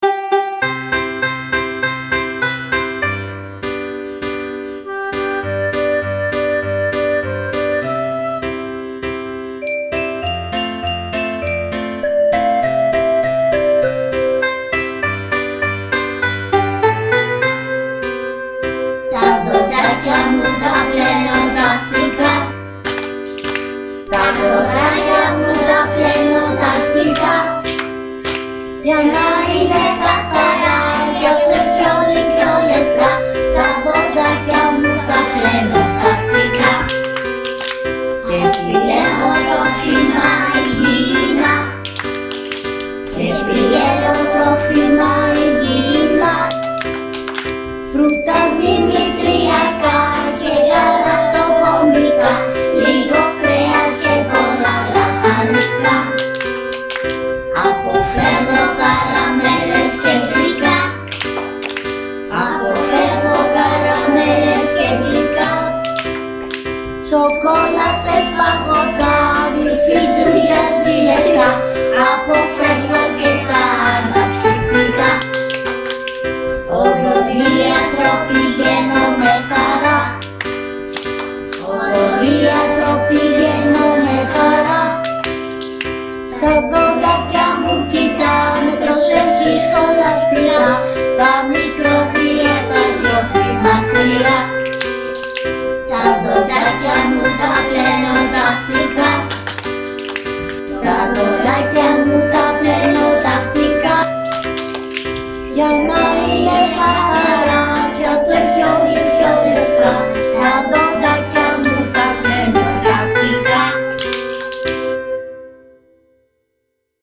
Τραγούδι σχετικό με τη φροντίδα των δοντιών,  που φτιάξαμε και ηχογραφήσαμε στο σχολείο!